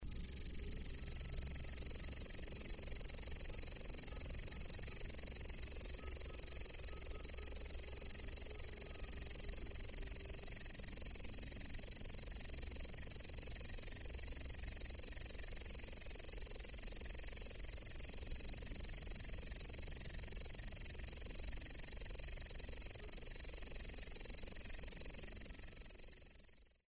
Ab Mitte Juli wird er abgelöst durch das Grüne Heupferd… der Gesang ist ebenfalls weit hörbar.
So tönt das Heupferd
Grosses Heupferd
heupferd-natur-konkret.mp3